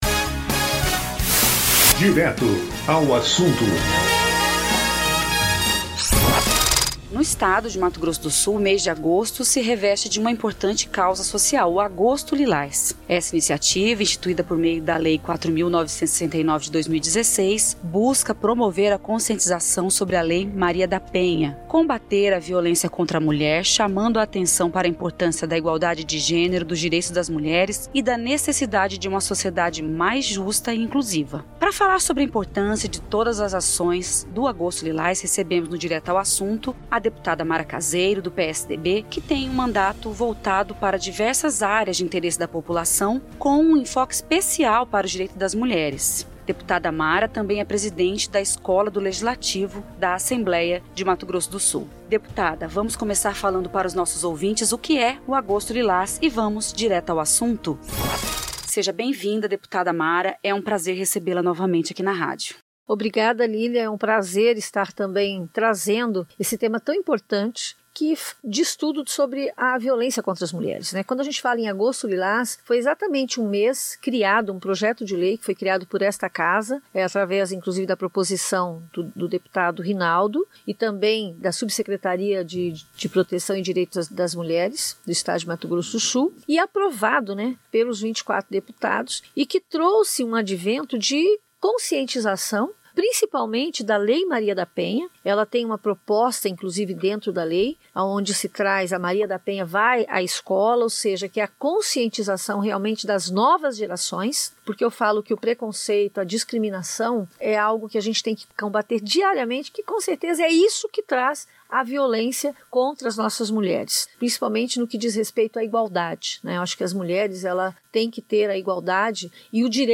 A tradução da Lei Maria da Penha para o Guarani é um dos grandes avanços nas ações do Agosto Lilás no estado, como conta a deputada Mara Caseiro do PSDB na entrevista ao Direto Assunto da Rádio da Assembleia Legislativa de Mato Grosso do Sul.